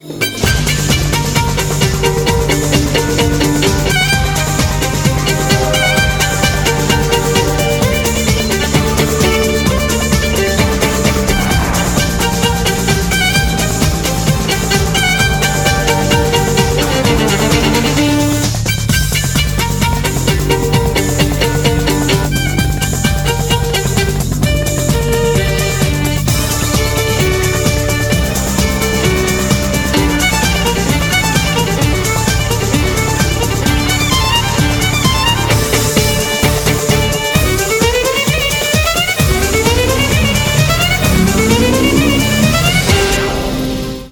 • Качество: 192, Stereo
скрипка